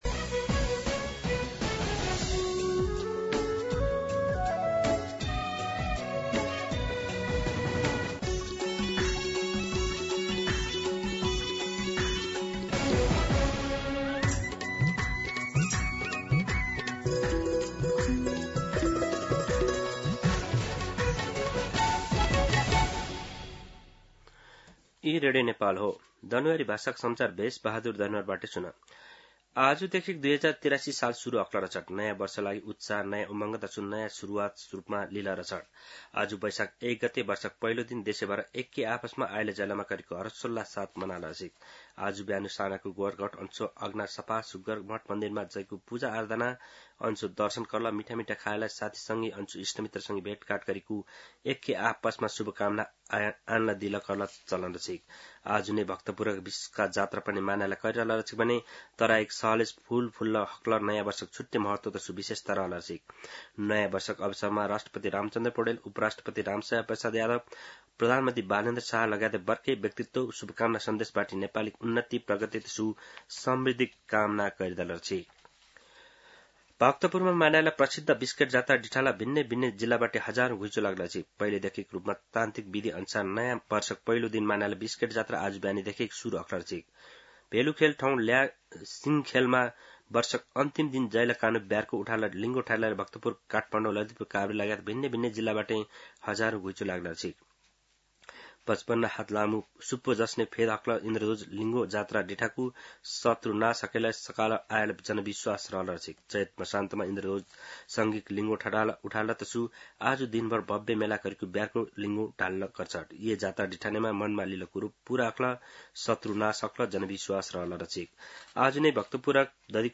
दनुवार भाषामा समाचार : १ वैशाख , २०८३
Danuwar-News-01.mp3